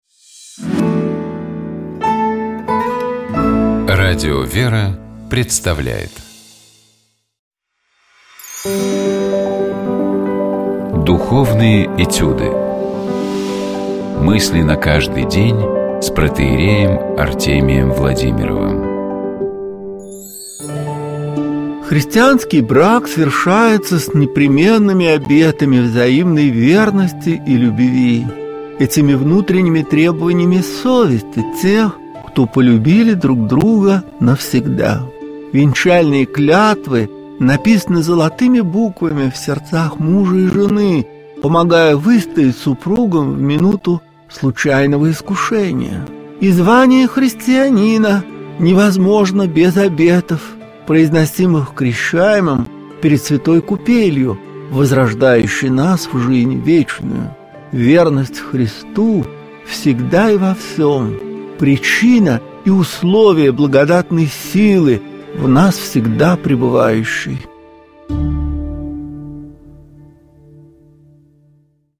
Ведущий программы: Протоиерей Артемий Владимиров